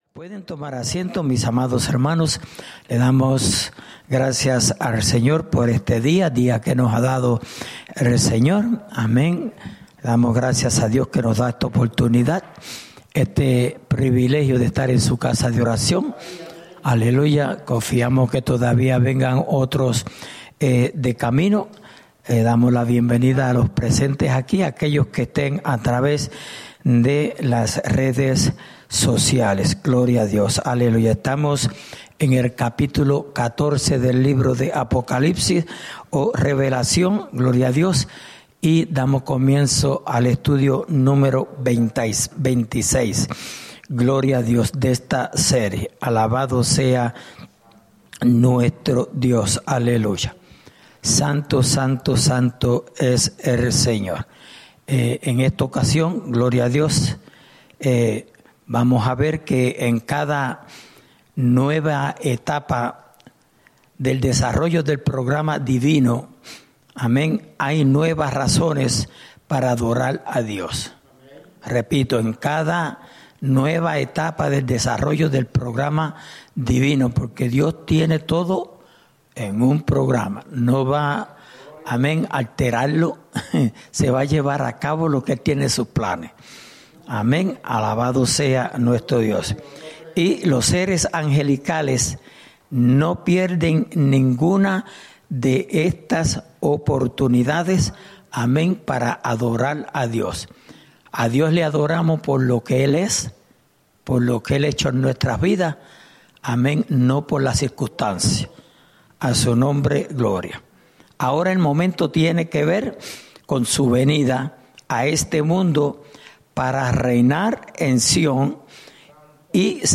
Estudio Bíblico: Libro de Apocalipsis (Parte 26)